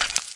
PixelPerfectionCE/assets/minecraft/sounds/mob/skeleton/step2.ogg at mc116
step2.ogg